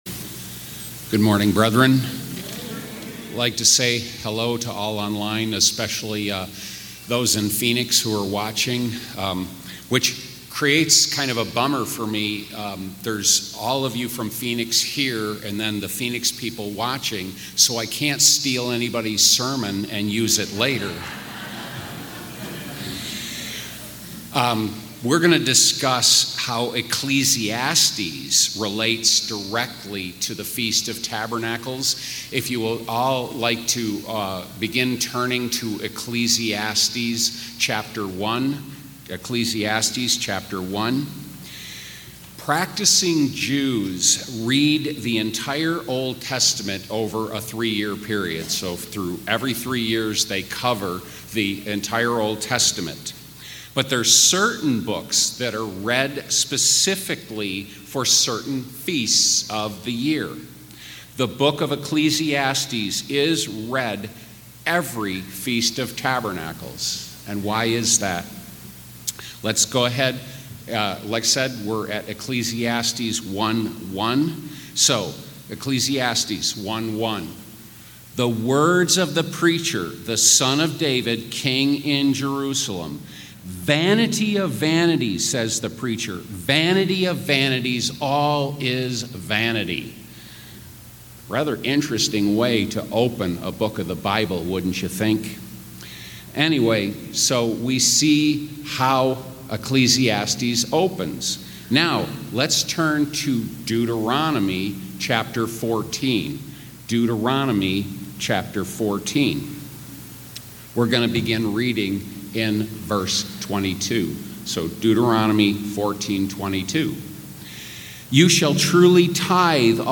Sermons
Given in Temecula, California